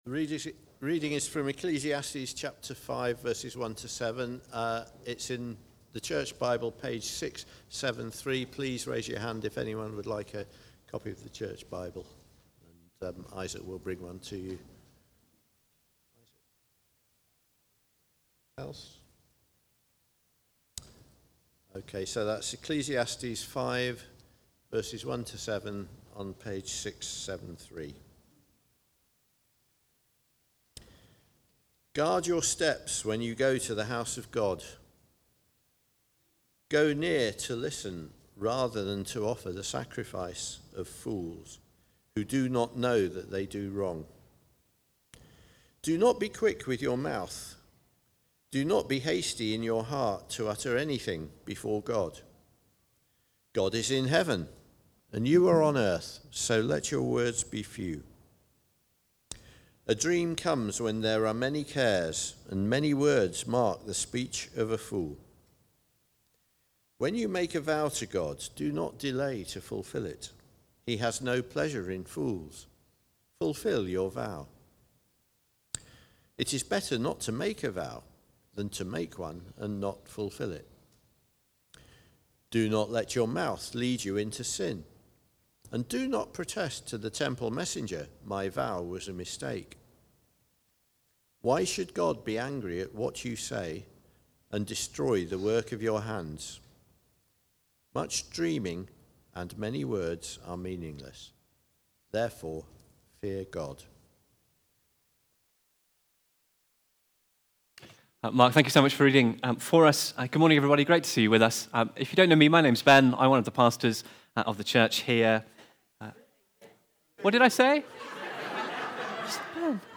Fear the Lord (Ecclesiastes 5:1-7) from the series Chasing After the Wind. Recorded at Woodstock Road Baptist Church on 05 October 2025.